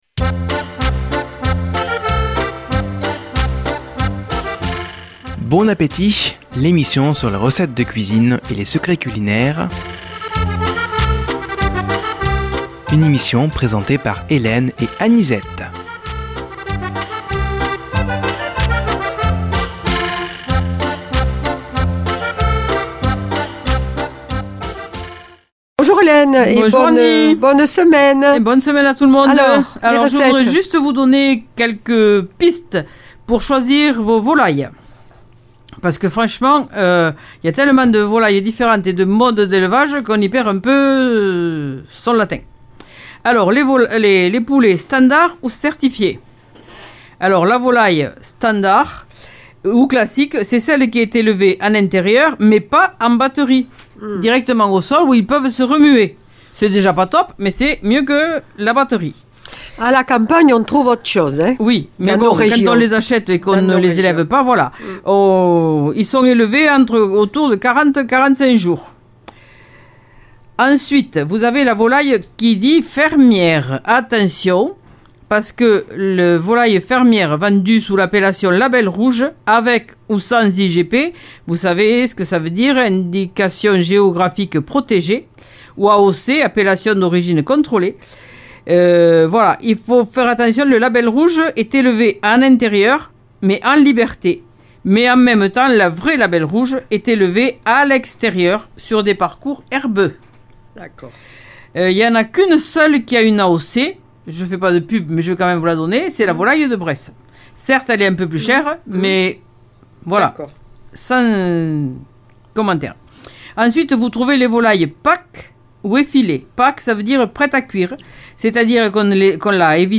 Une émission présentée par
Présentatrices